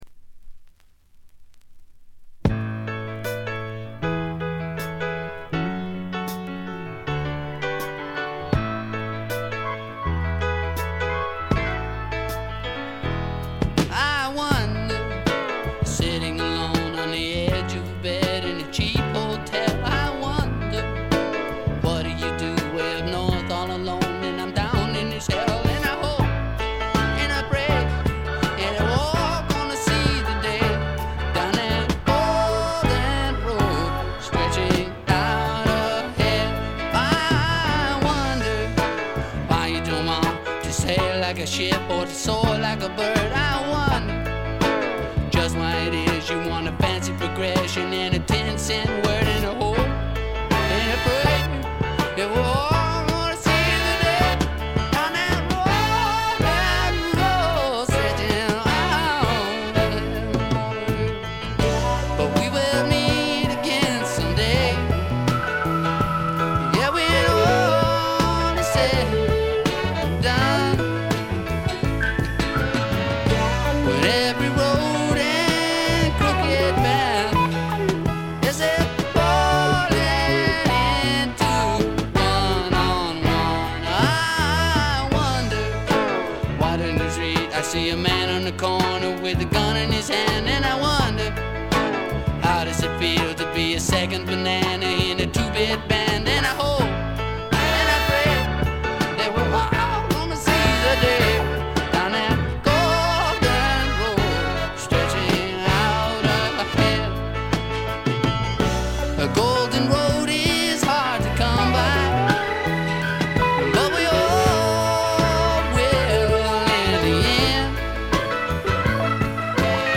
ごくわずかなノイズ感のみ。
独特のしゃがれた渋いヴォーカルで、スワンプ本線からメローグルーヴ系までをこなします。
試聴曲は現品からの取り込み音源です。